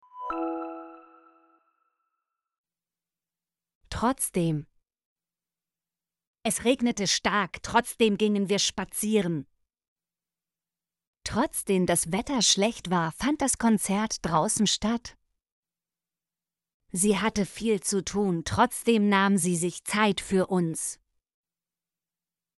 trotzdem - Example Sentences & Pronunciation, German Frequency List